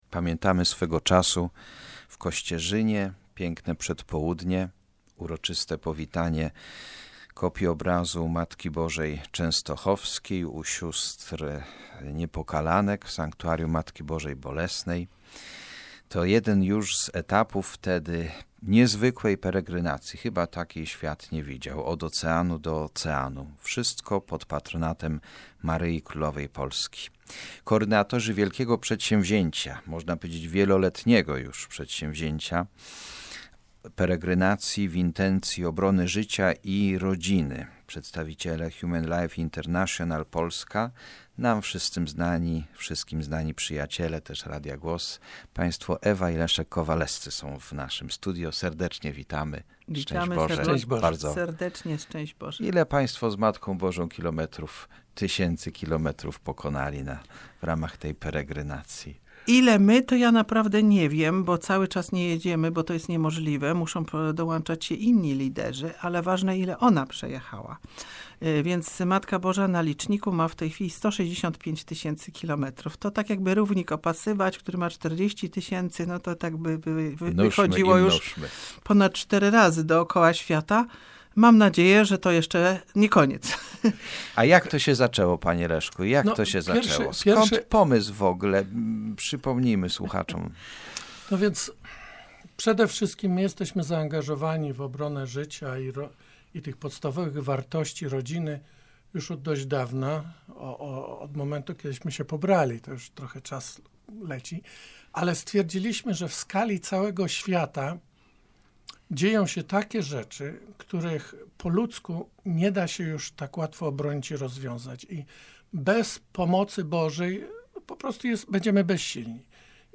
W środę 24 lutego 2016 Radio Głos z Pelplina wyemitowało wywiad z międzynarodowymi koordynatorami peregrynacji Ikony Częstochowskiej "Od Oceanu do Oceanu" przez